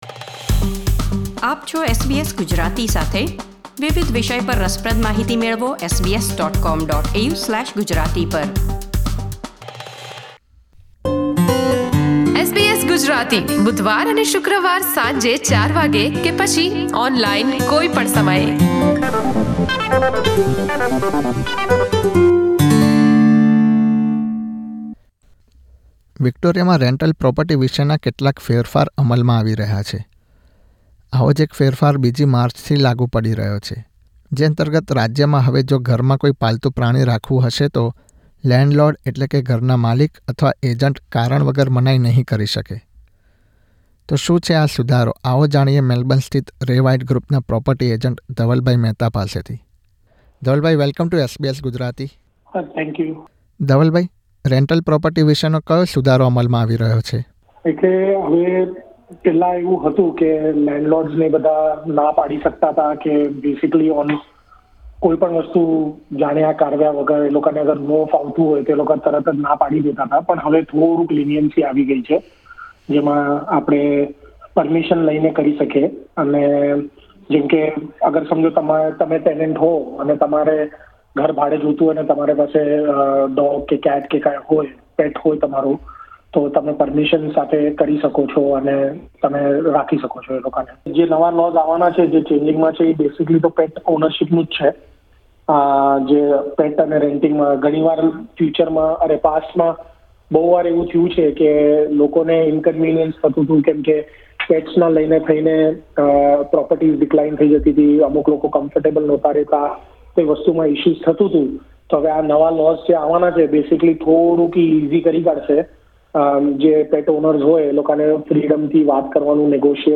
વાતચીત....